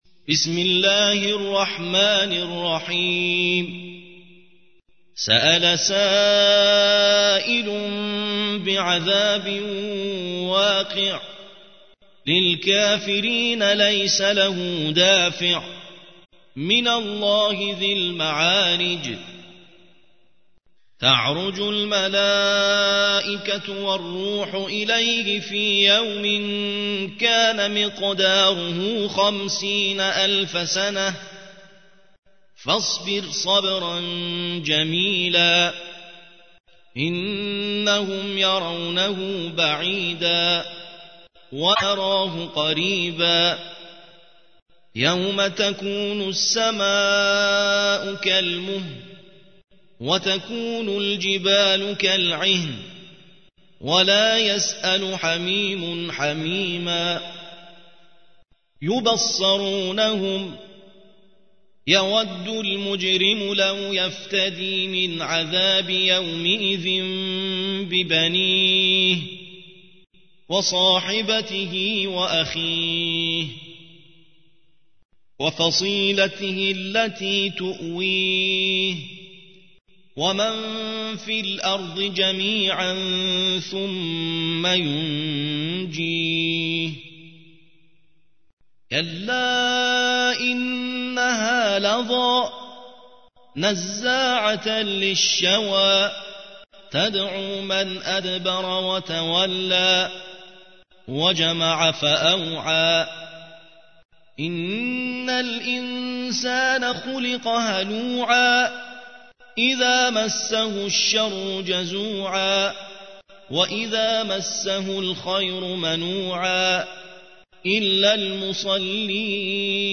70. سورة المعارج / القارئ